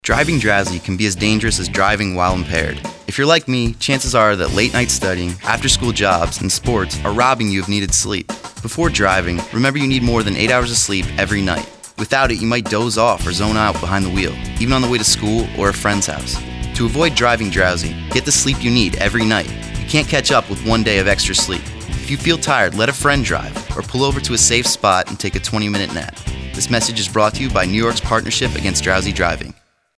High School Student :30 Radio PSA.